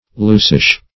loosish - definition of loosish - synonyms, pronunciation, spelling from Free Dictionary Search Result for " loosish" : The Collaborative International Dictionary of English v.0.48: Loosish \Loos"ish\ (l[=oo]s"[i^]sh), a. Somewhat loose.
loosish.mp3